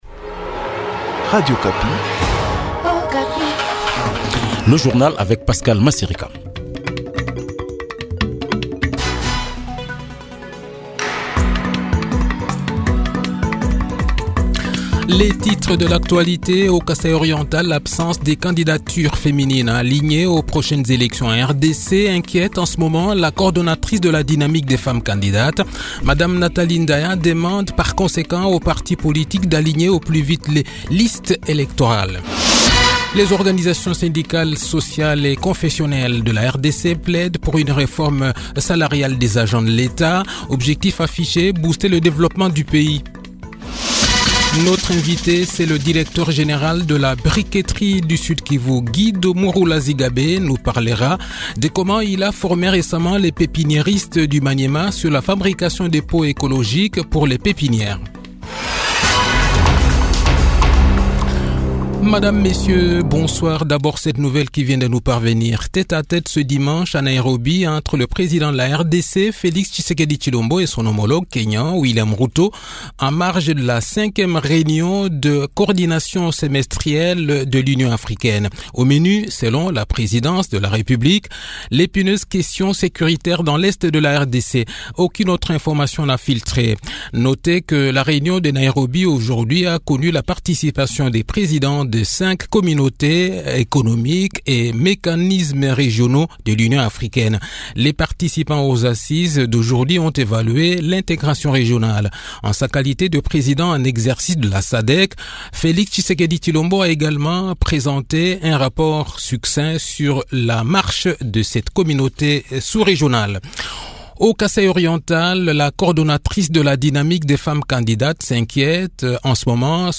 Le journal de 18 h, 16 Juillet 2023